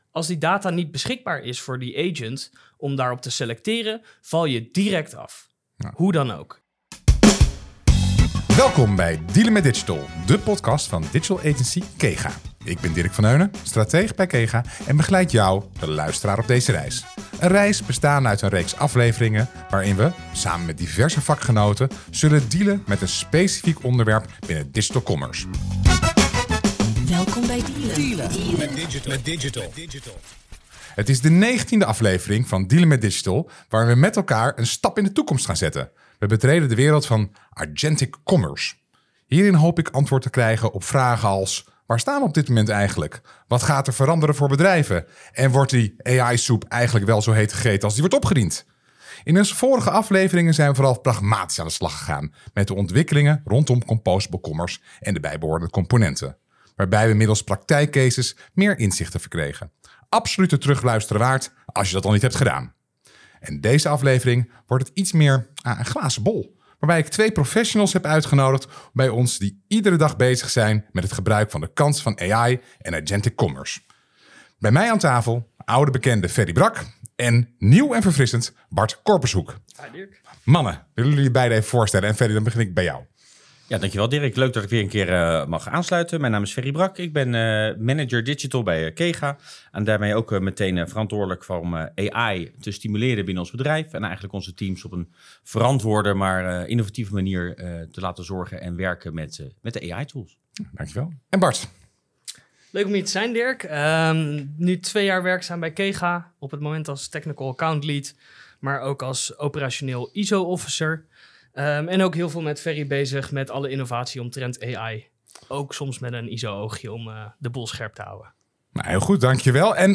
In deze podcast voert digital agency Kega open keukentafel-gesprekken met vakgenoten over de digitale vraagstukken van vandaag en morgen.